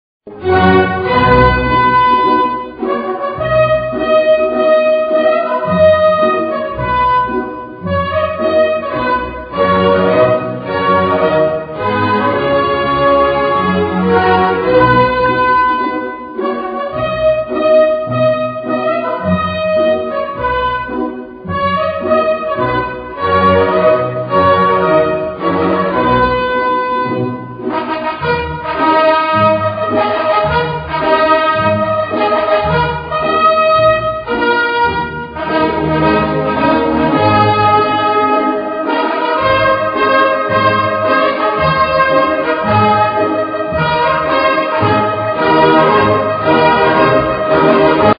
• Качество: 320, Stereo
громкие
без слов
скрипка
инструментальные
виолончель
опера
величественные